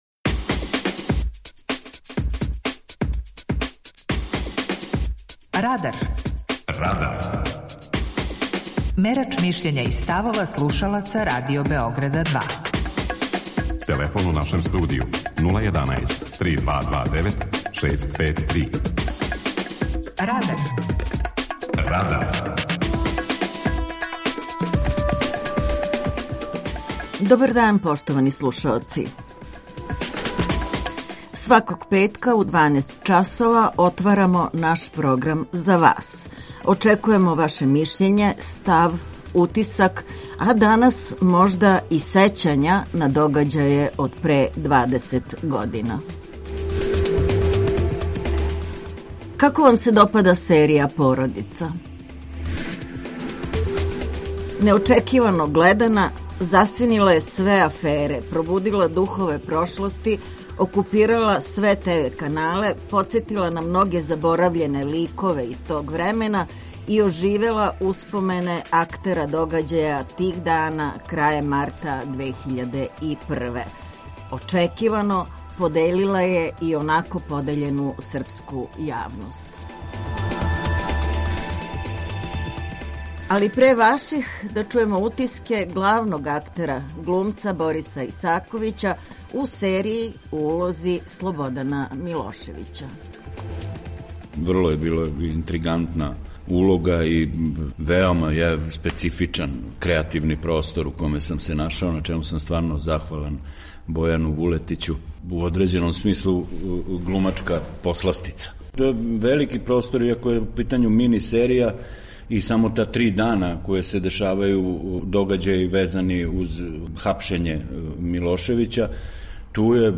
Питање Радара: Како вам се допада серија Породица? преузми : 18.25 MB Радар Autor: Група аутора У емисији „Радар", гости и слушаоци разговарају о актуелним темама из друштвеног и културног живота.